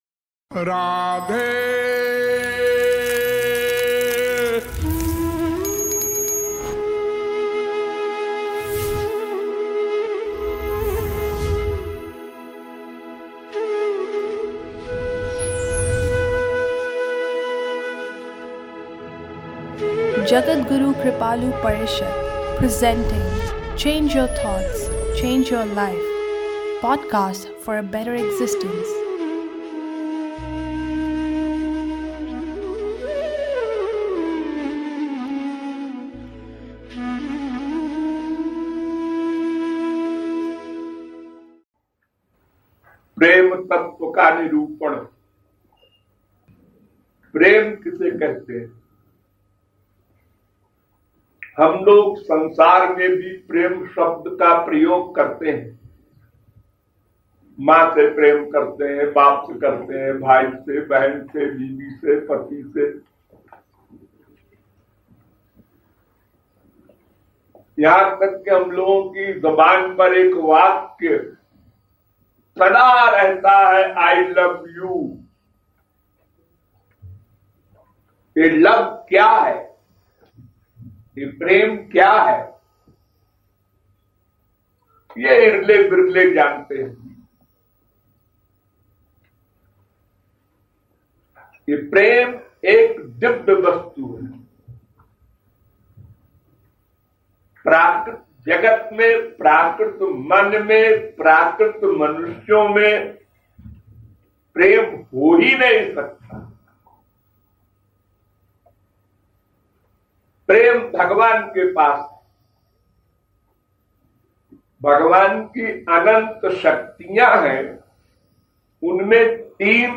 In this critical lecture